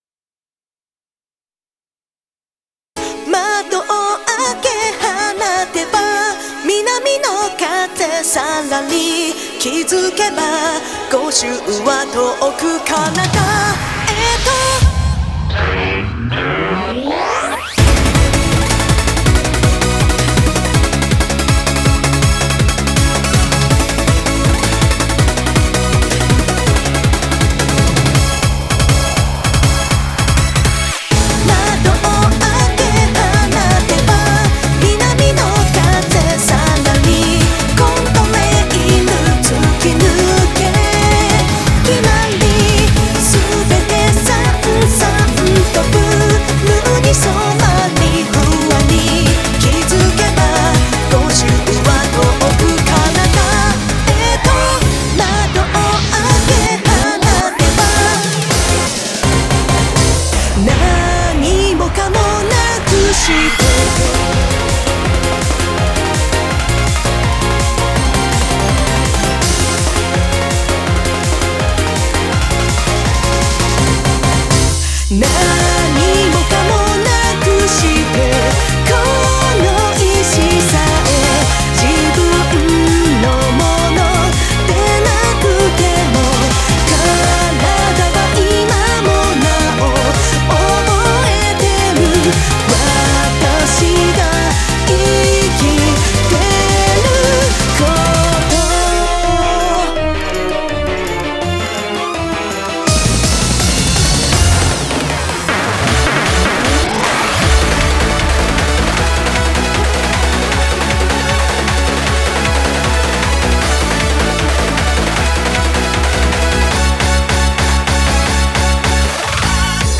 ★VOCAL
ミネラルウォーターサウンドスタジオ